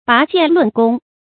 拔劍論功 注音： ㄅㄚˊ ㄐㄧㄢˋ ㄌㄨㄣˋ ㄍㄨㄙ 讀音讀法： 意思解釋： 指爭論功勞激烈到幾乎動武。